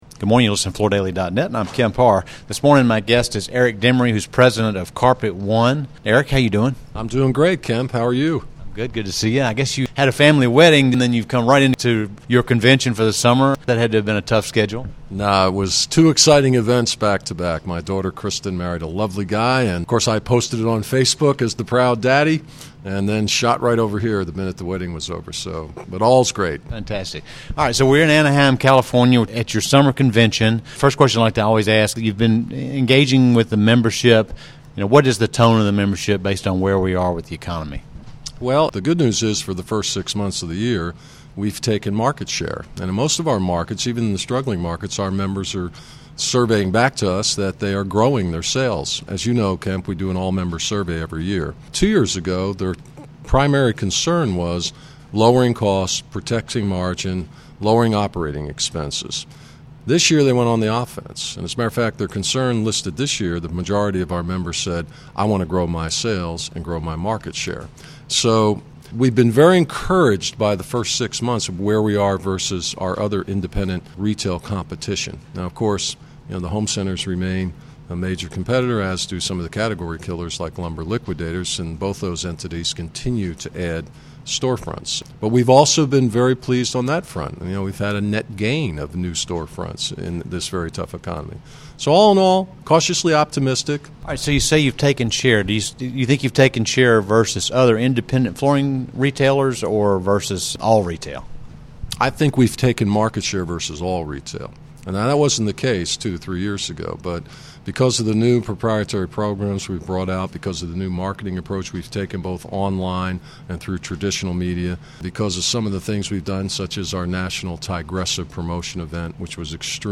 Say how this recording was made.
Listen to the interview recorded at the CCA Global Convention in Anneheim, CA to hear more about Carpet One's retail approach and about some of the new products being rolled out at the convention.